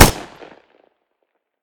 smg-shot-04.ogg